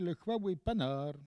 Langue Maraîchin
locutions vernaculaires